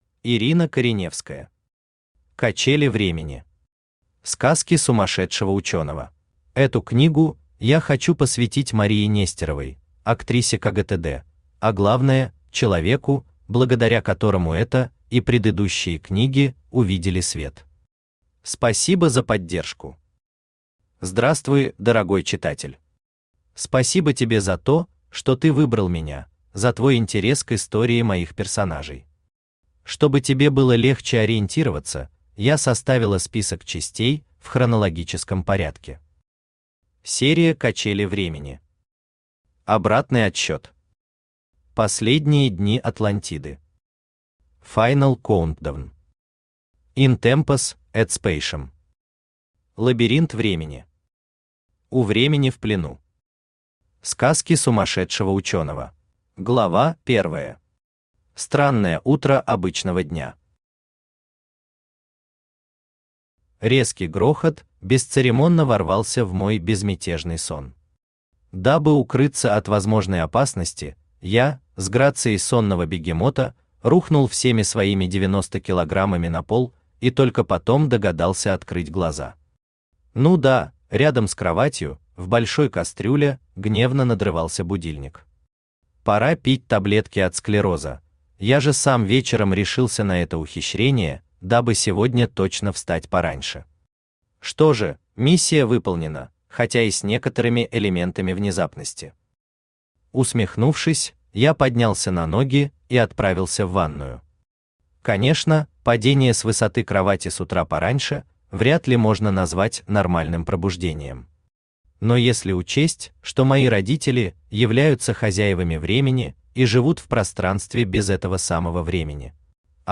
Сказки сумасшедшего ученого Автор Ирина Михайловна Кореневская Читает аудиокнигу Авточтец ЛитРес.